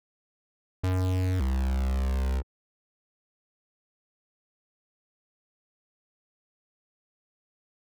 computersaysno.wav